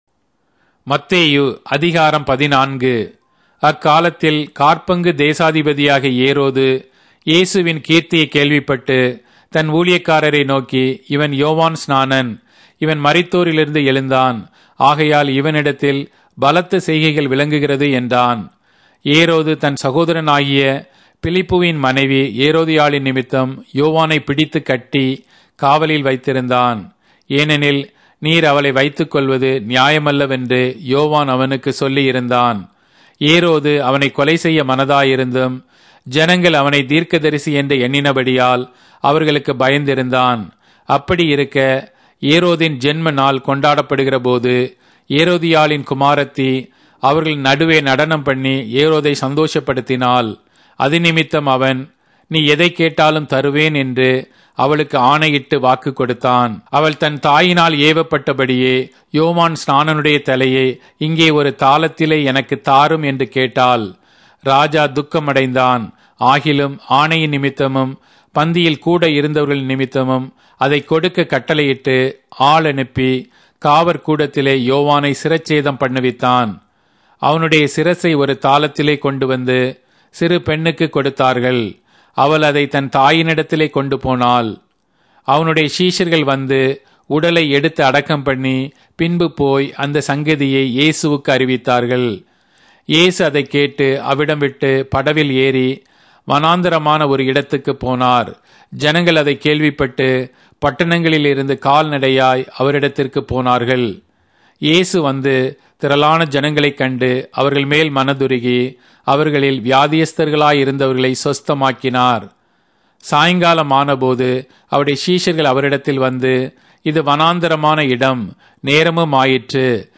Tamil Audio Bible - Matthew 23 in Ervbn bible version